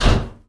metal_med_impact_01.wav